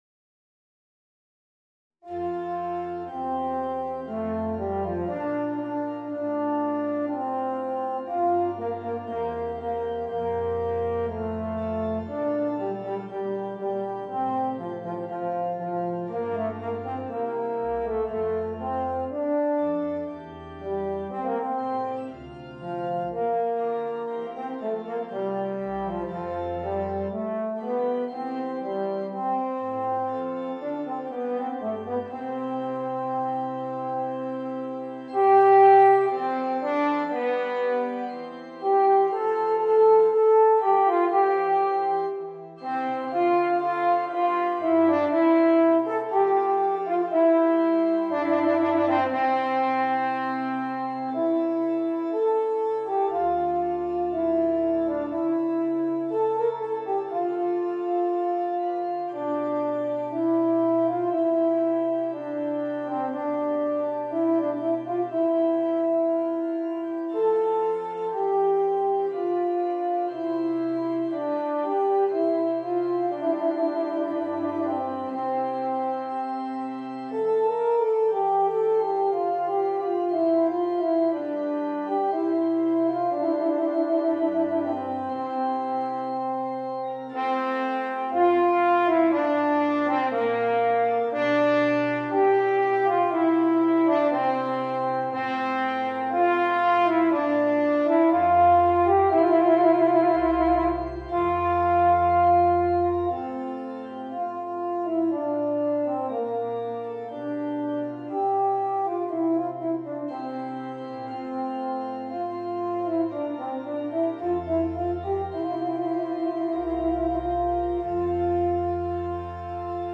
Voicing: Horn and Piano